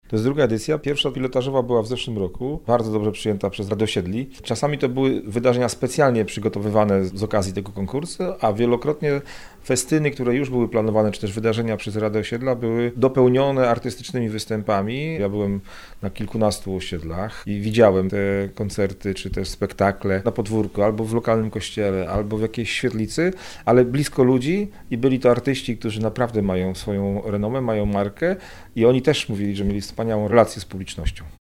Mówi Krzysztof Maj, Członek Zarządu Województwa Dolnośląskiego.